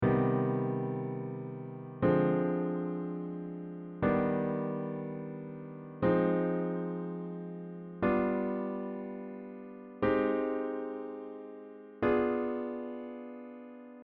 C-Hm-Chord
C-Hm-Chord.mp3